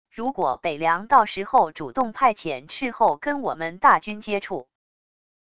如果北凉到时候主动派遣斥候跟我们大军接触wav音频生成系统WAV Audio Player